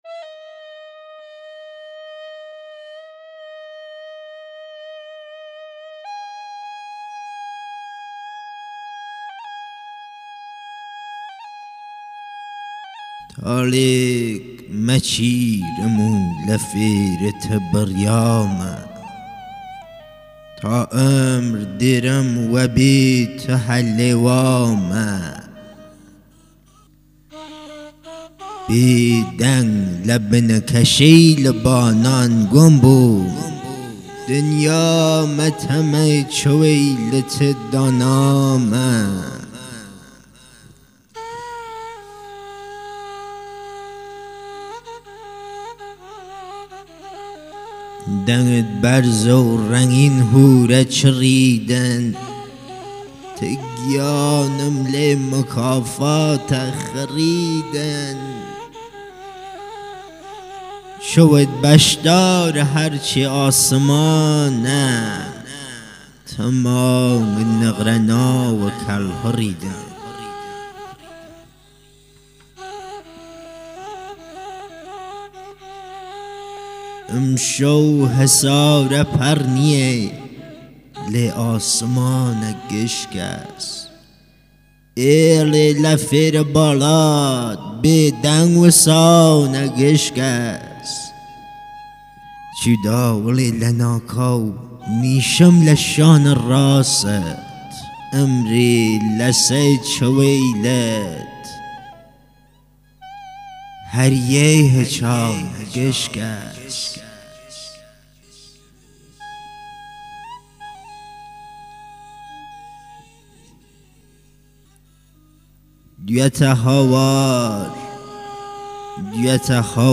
آلبوم صوتی مجموعه شعر کردی کلهری
با نوای زیبا و دلربای اساتید صاحب فن "کمانچه نوازی
پیانو نوازی اساتید زنده یاد بابک بیات و انوشیروان روحانی
قره نی نوازی